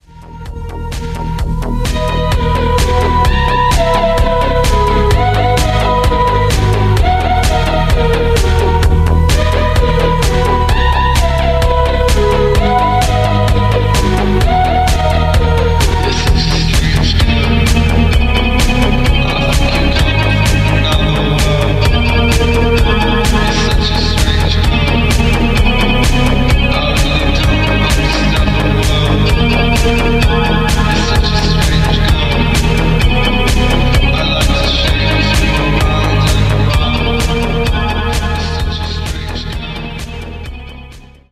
• Качество: 320, Stereo
атмосферные
приятные
расслабляющие
synthwave
relax
Меланхолия